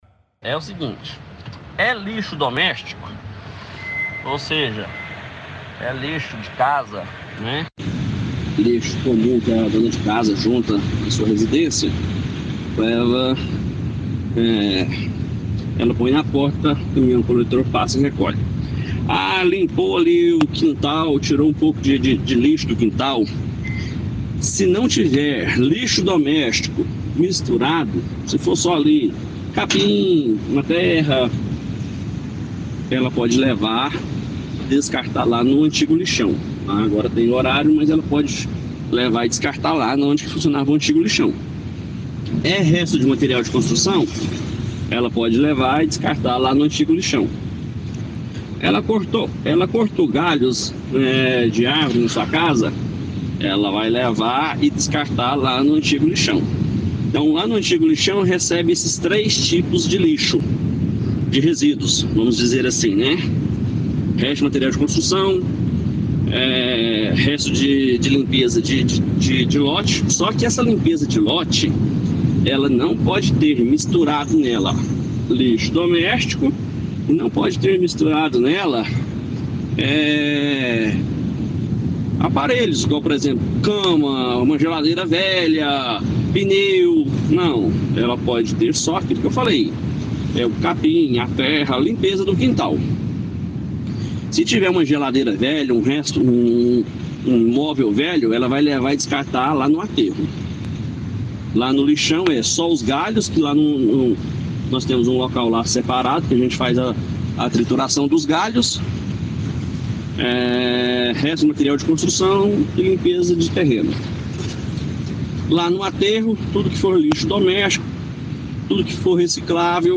Mas os lixos não domésticos ainda podem ser levados para aquela área. No áudio abaixo o secretário municipal do Meio Ambiente, Rodrigo da Oca, explica quais os tipos de lixo ainda podem ser destinados ao antigo lixão: